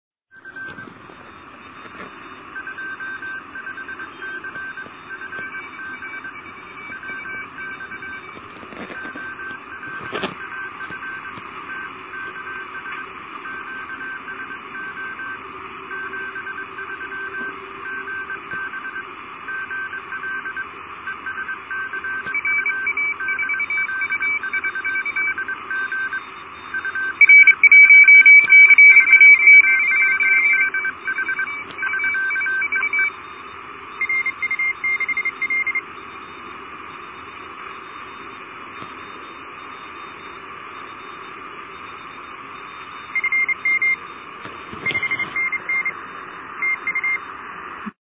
14060-pileup_mono.mp3